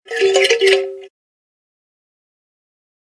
descargar sonido mp3 caja musica